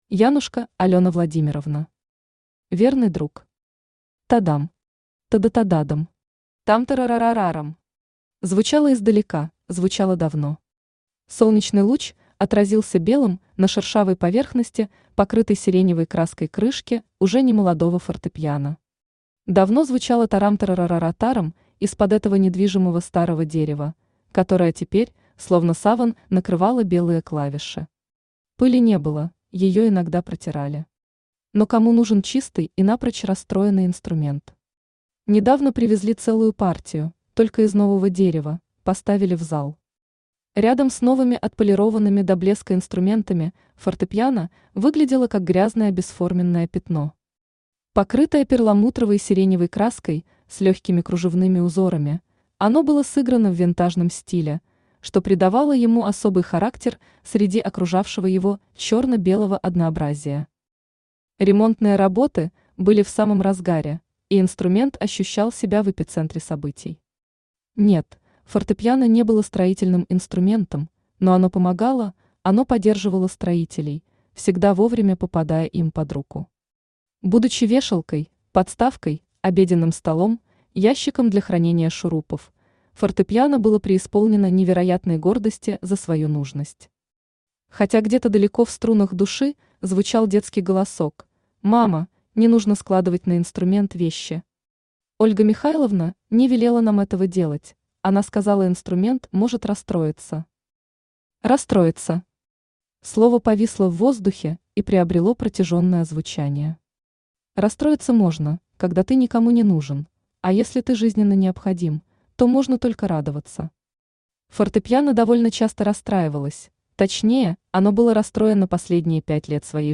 Аудиокнига Верный друг | Библиотека аудиокниг
Aудиокнига Верный друг Автор Алёна Владимировна Янушко Читает аудиокнигу Авточтец ЛитРес.